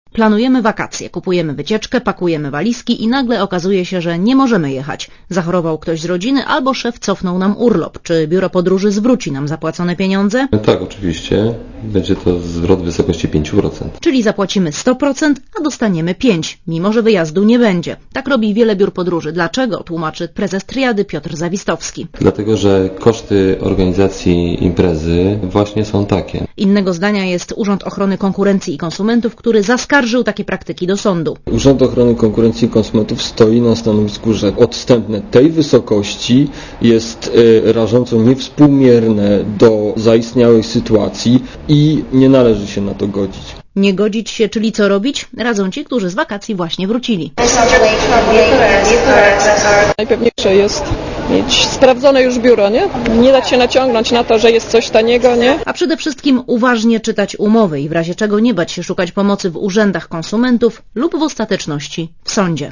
Relacja reportera Radia ZET Według danych Urzędu Ochrony Konkurencji i Konsumentów w większość krajów Unii Europejskiej w przypadku rezygnacji z wyjazdu w ostatniej chwili, zwraca klientom 40% kosztów wycieczki.